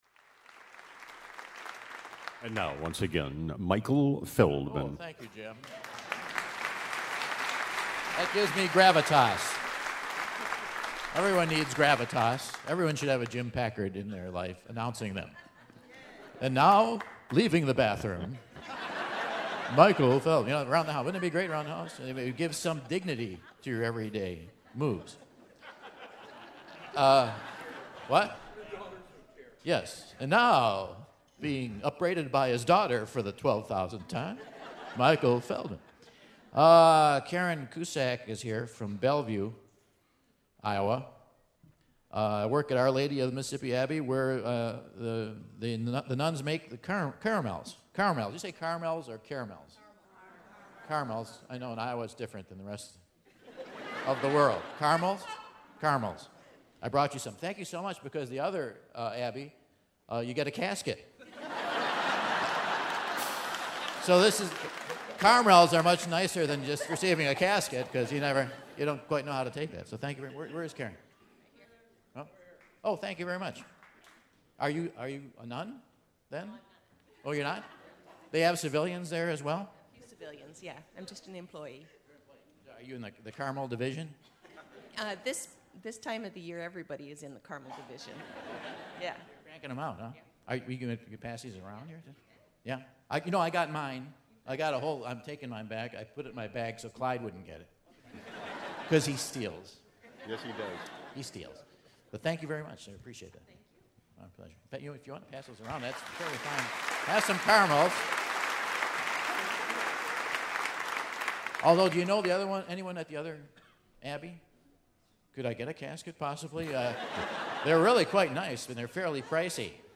Quiz A - September 15, 2012 in Dubuque, IA | Whad'ya Know?